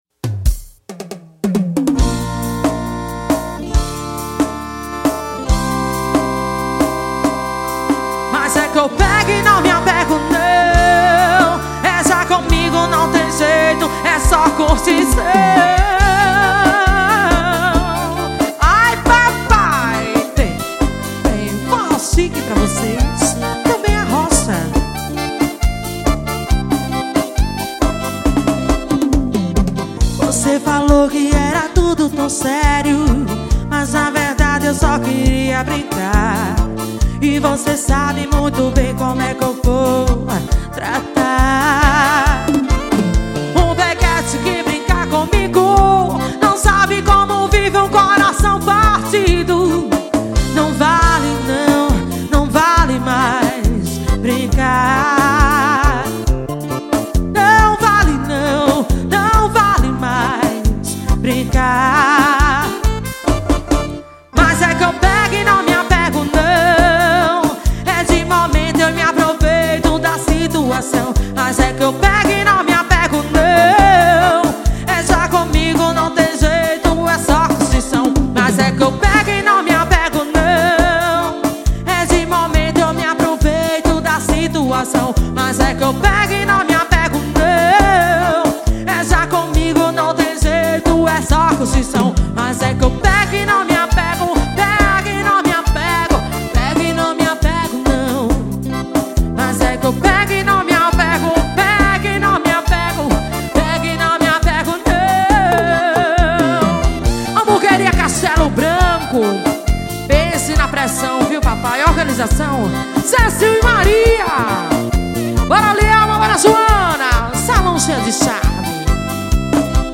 Composição: forro.